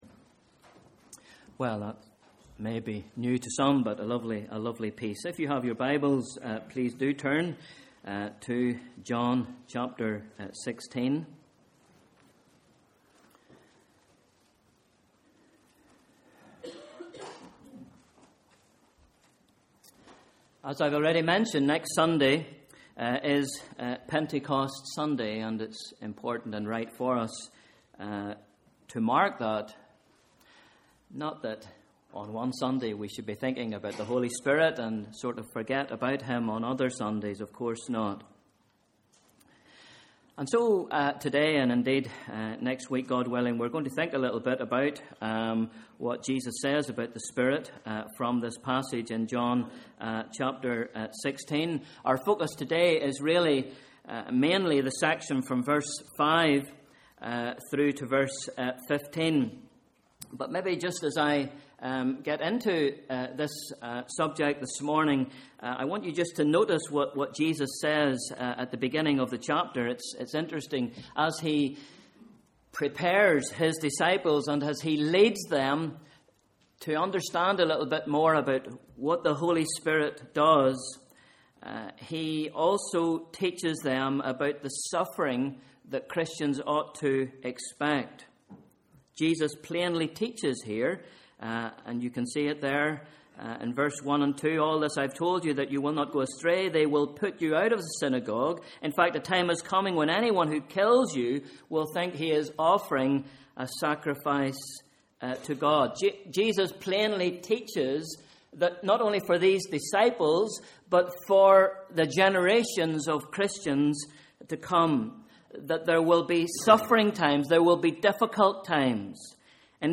Morning Service: Sunday 12th May 2013